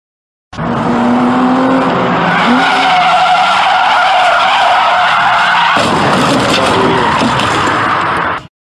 Car Crash By Skid